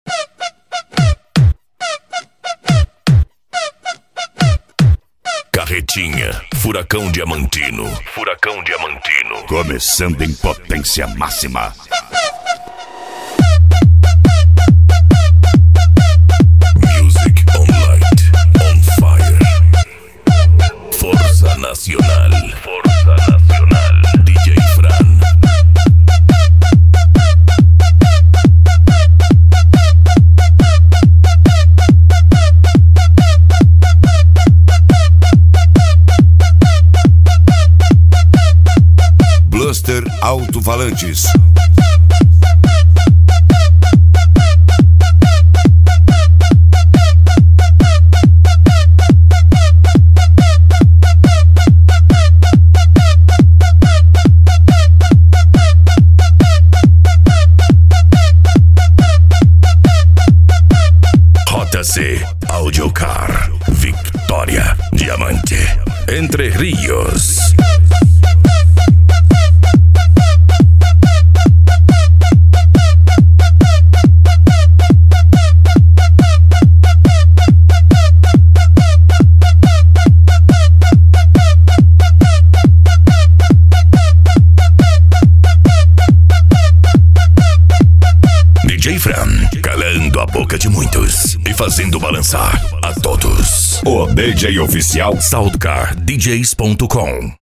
Bass
PANCADÃO
Remix
Trance Music